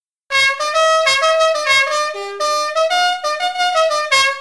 Play, download and share Kasatchok Horn original sound button!!!!
kasatchok-horn.mp3